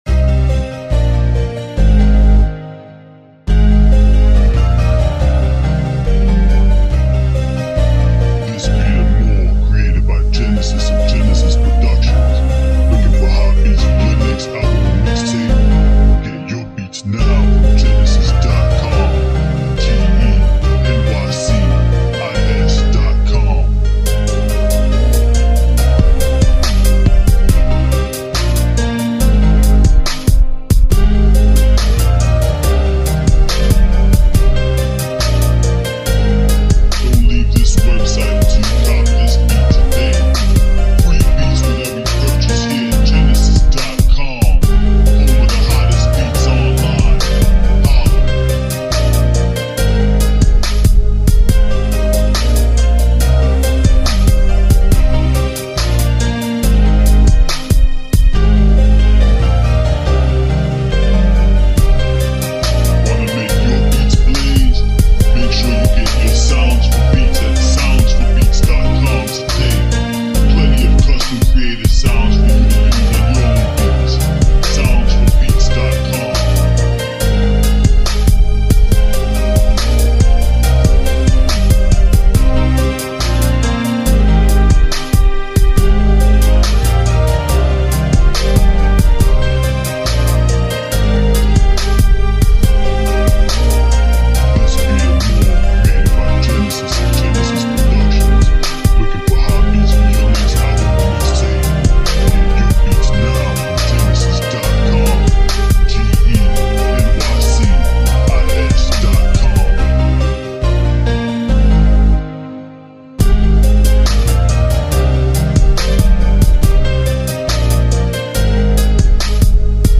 Seductive Story R&B Beat